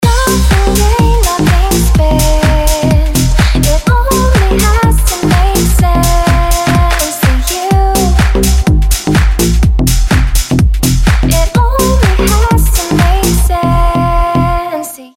• Качество: 320, Stereo
женский вокал
dance
club
nu disco
house
Indie Dance